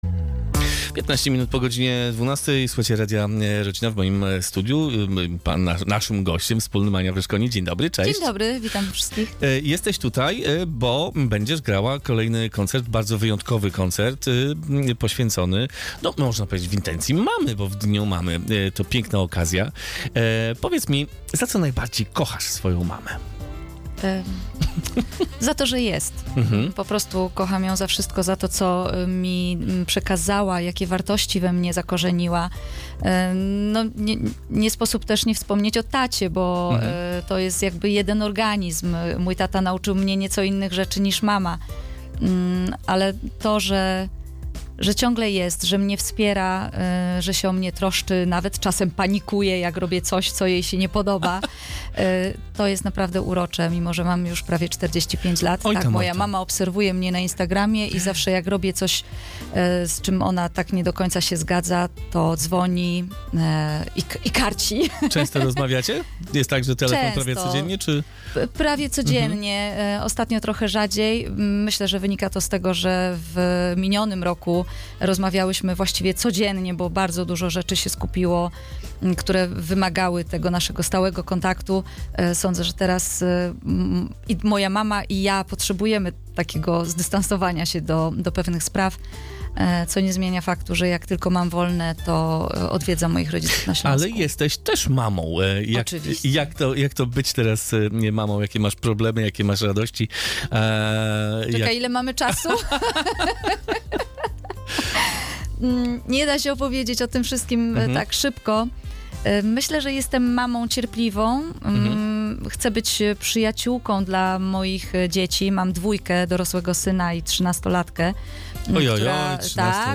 Gościem Radia Rodzina była Ania Wyszkoni, która opowiedziała o zbliżającym się koncercie z okazji Dnia Matki, rodzinnych wartościach oraz o tym, co znaczy dla niej bycie mamą.
CAŁA ROZMOWA: